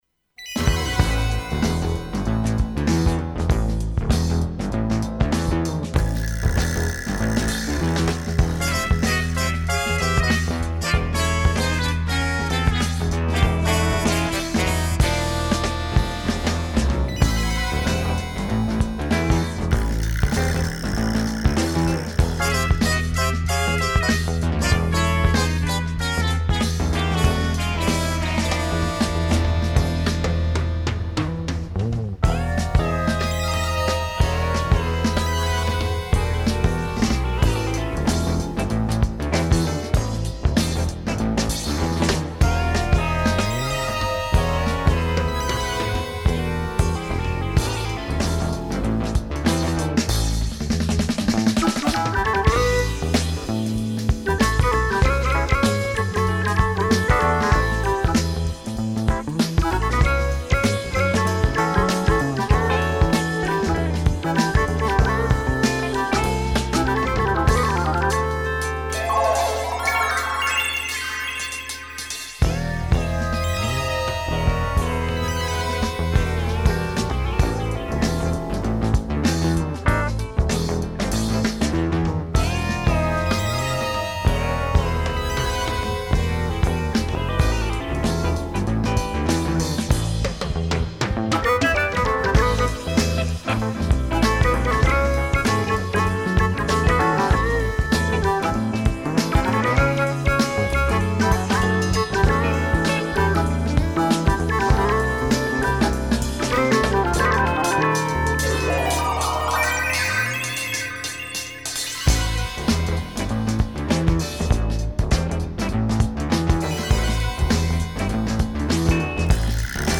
reducedfunky moog cover